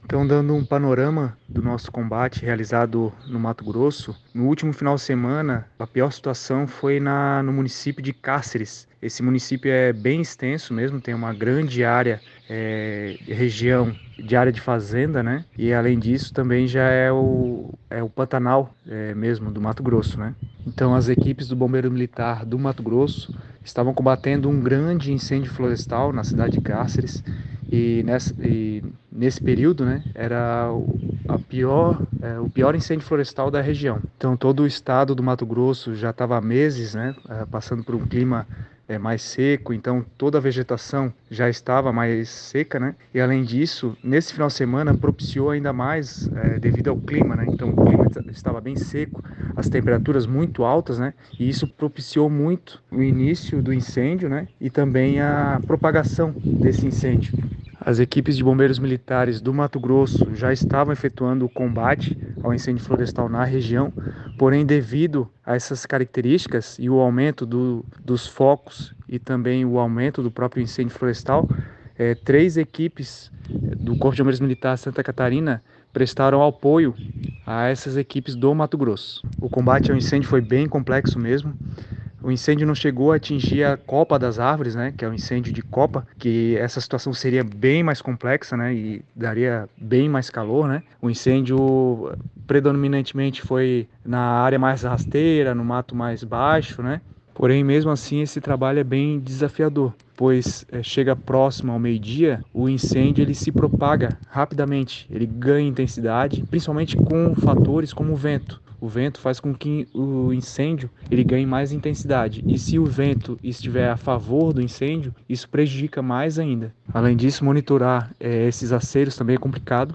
SECOM-Sonora-Capitao-Bombeiros-Incendios-Mato-Grosso.mp3